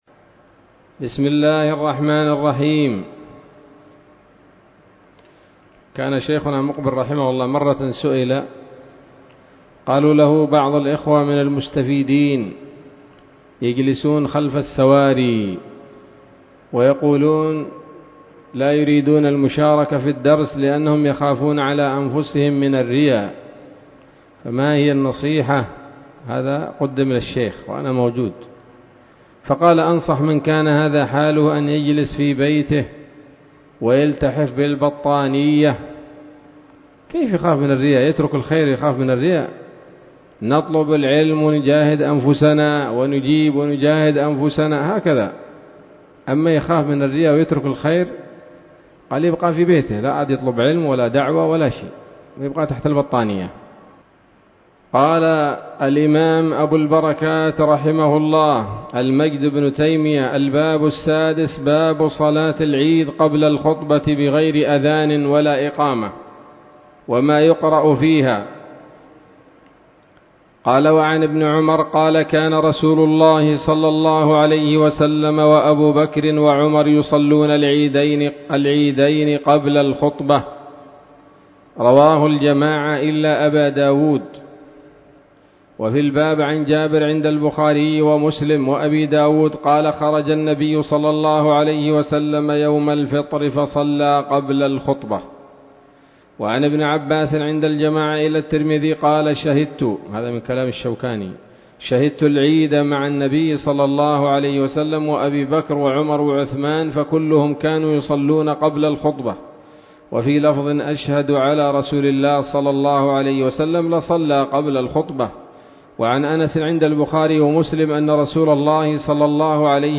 الدرس الثامن من ‌‌‌‌كتاب العيدين من نيل الأوطار